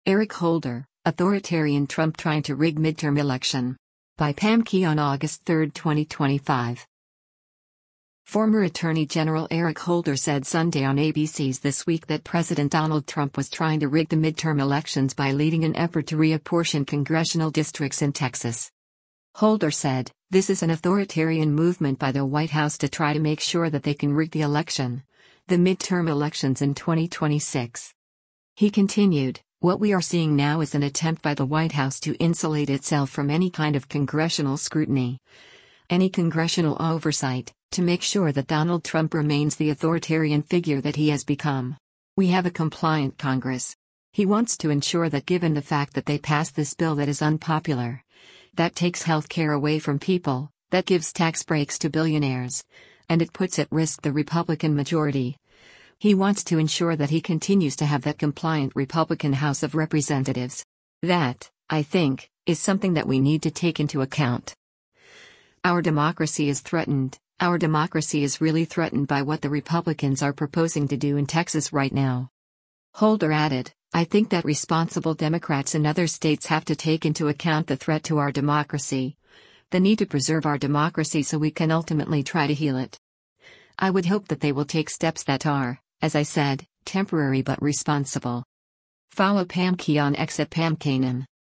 Former Attorney General Eric Holder said Sunday on ABC’s “This Week” that President Donald Trump was trying to rig the midterm elections by leading an effort to reapportion congressional districts in Texas.